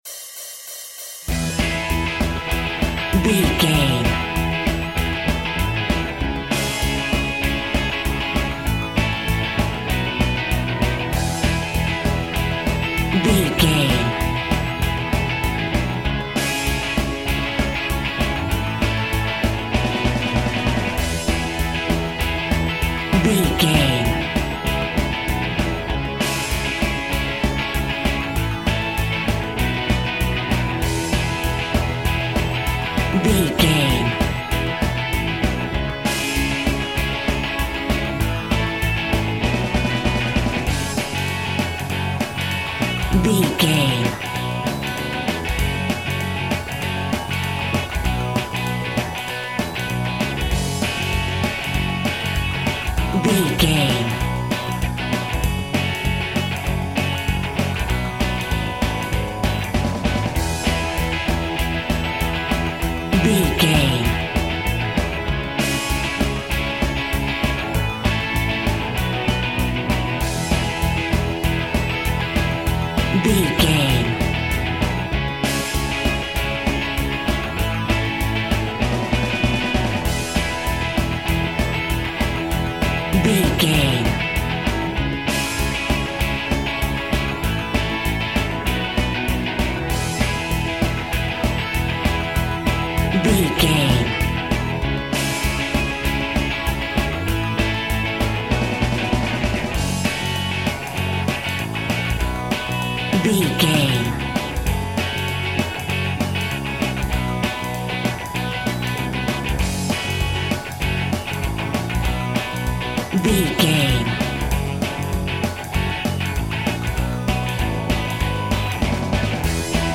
Modern Indie Pop Rock Music Cue.
Epic / Action
Fast paced
Ionian/Major
Fast
distortion
indie music
pop rock music
drums
bass guitar
electric guitar
piano
hammond organ